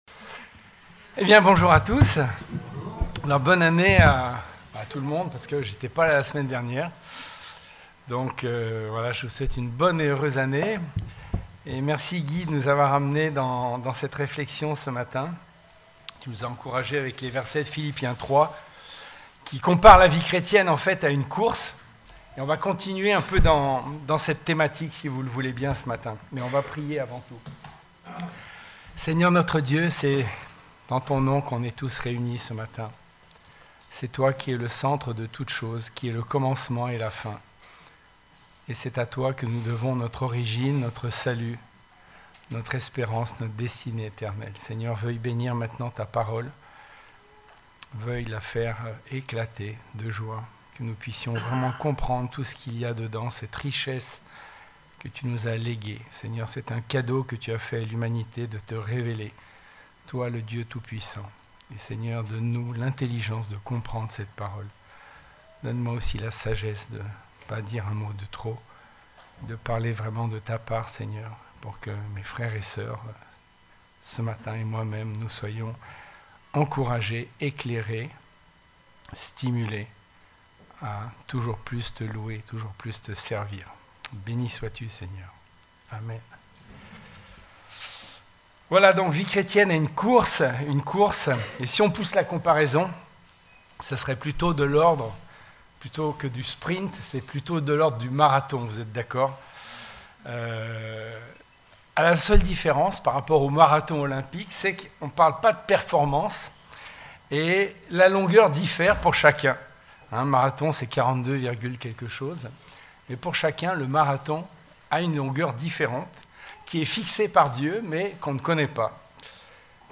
Type De Service: Culte Dimanche